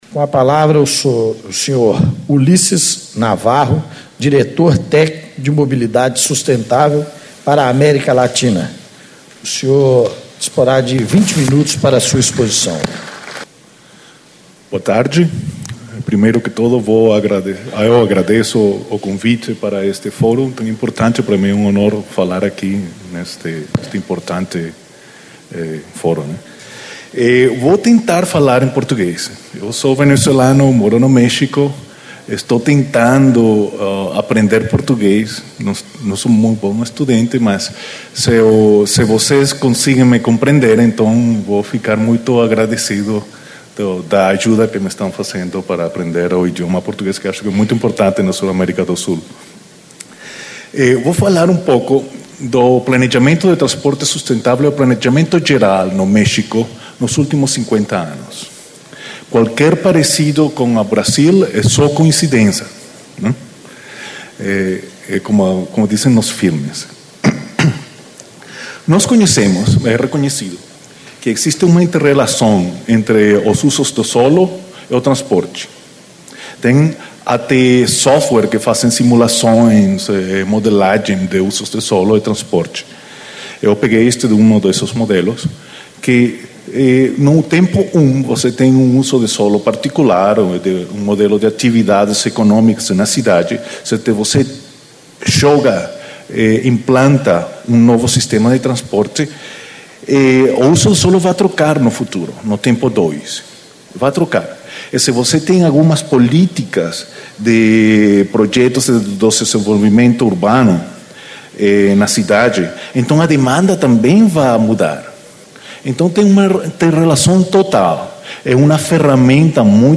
Encontro Estadual do Fórum Técnico Mobilidade Urbana - Construindo Cidades Inteligentes
Discursos e Palestras